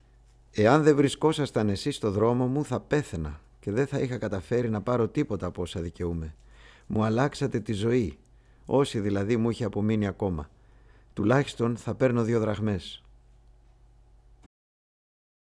Ηλικιωμένος 2